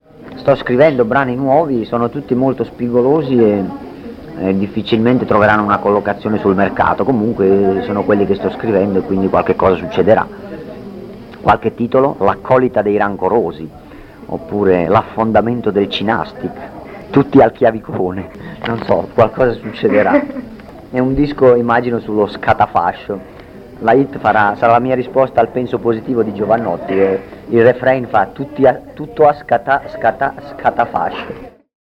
Dichiarazioni raccolte in occasione del concerto di Moncalvo (26 marzo 1996), quando l’organizzazione della serata di Ricaldone era ai suoi inizi.